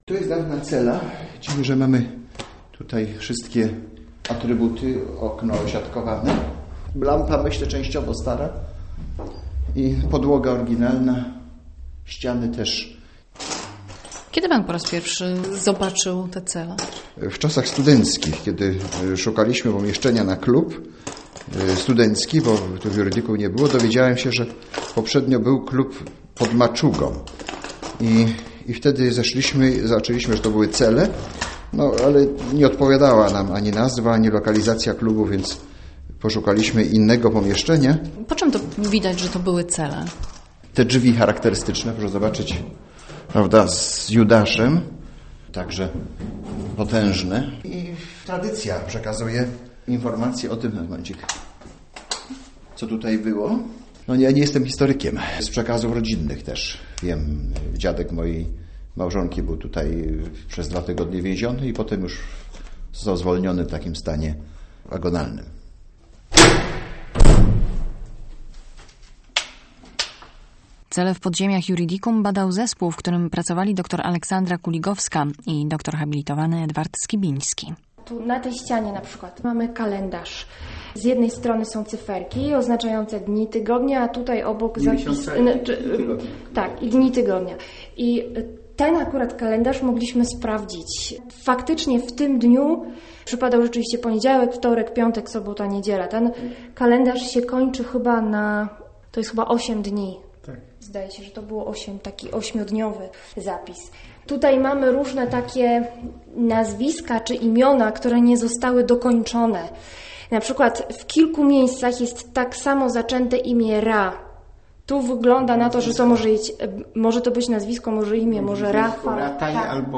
"Ktokolwiek wie..." - reportaż 2 marca 2013